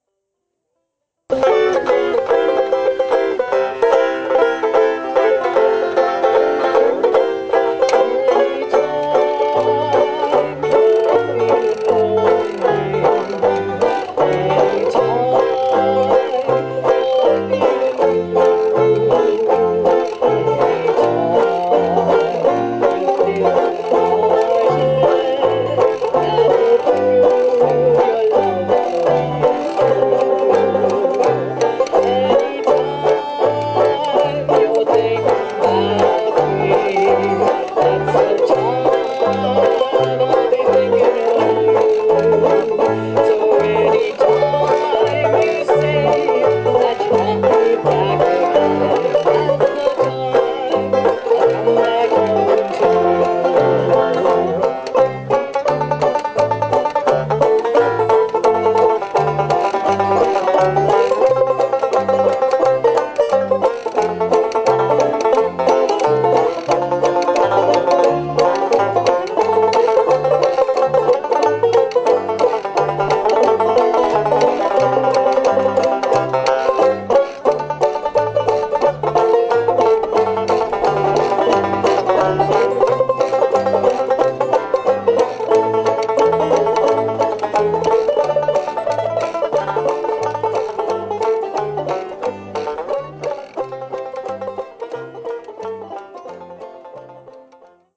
Listen to the Oakland Banjo Band perform "Anytime" (mp3)